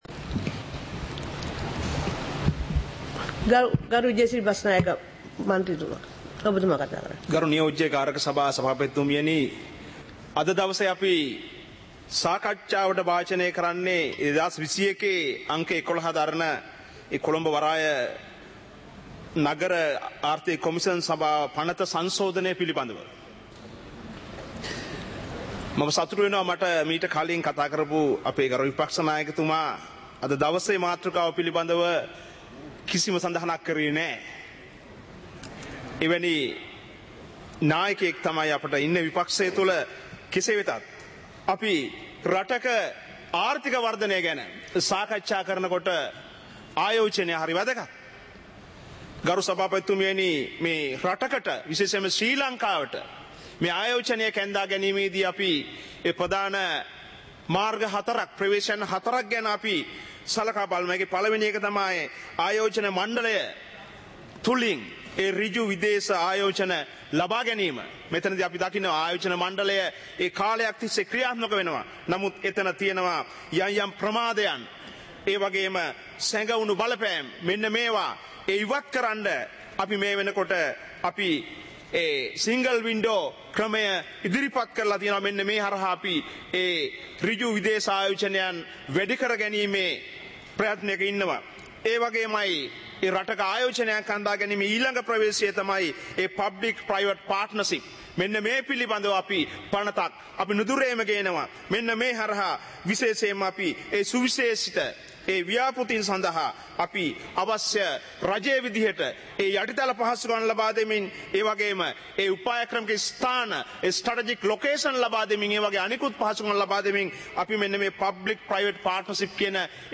සභාවේ වැඩ කටයුතු (2026-01-07)